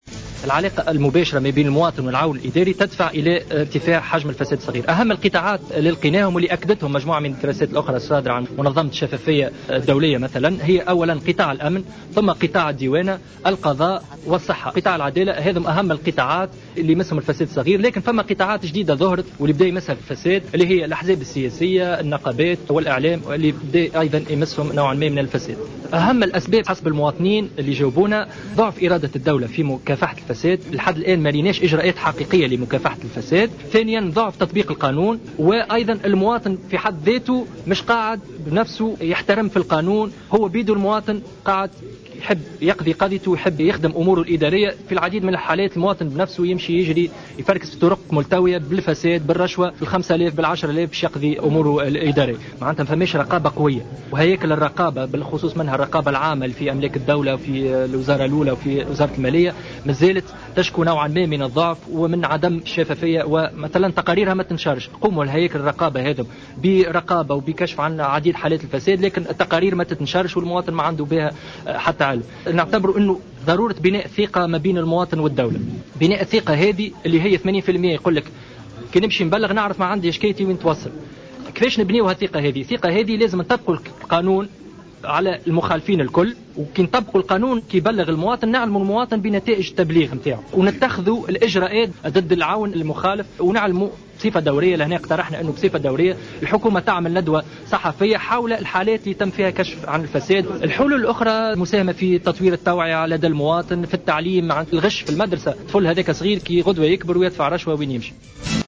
Lors d’une conférence de presse tenue ce mercredi, 04 mars 2015 pour la présentation de sa nouvelle étude sur la « petite corruption » en Tunisie